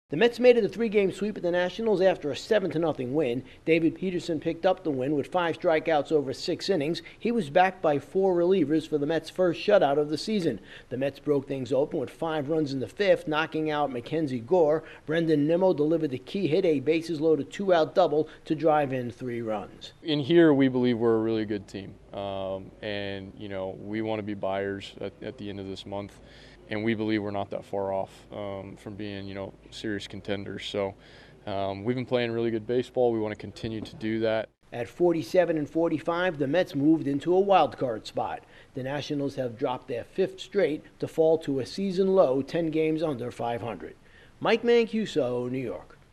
The Mets move two games over .500 for the first time since April 24th. Correspondent